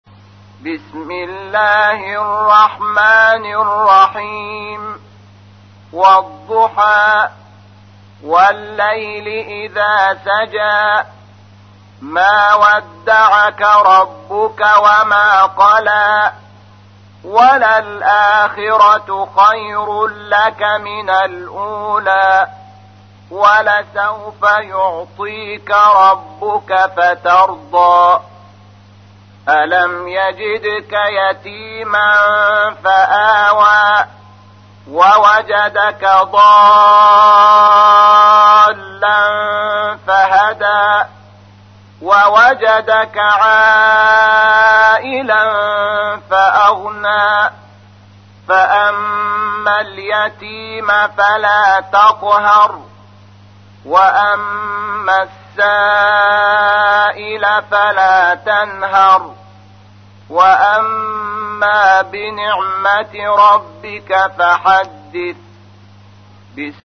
تحميل : 93. سورة الضحى / القارئ شحات محمد انور / القرآن الكريم / موقع يا حسين